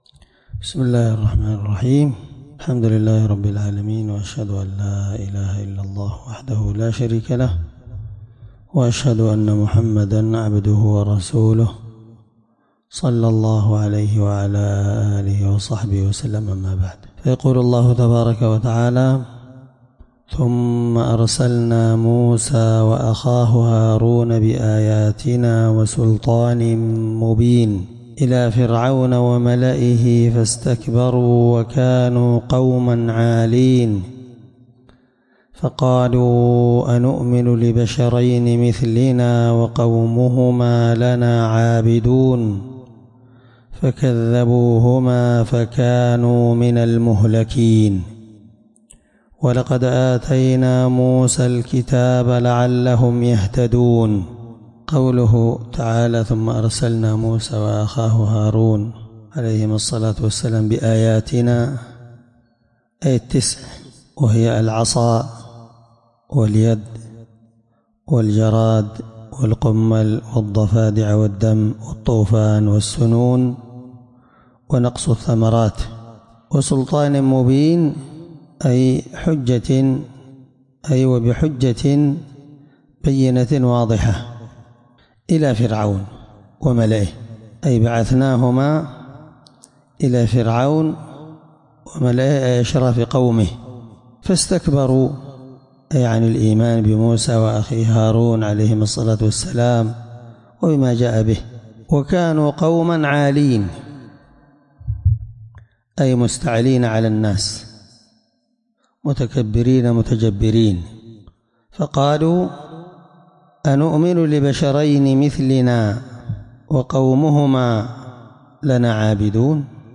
الدرس11تفسير آية (45-49) من سورة المؤمنون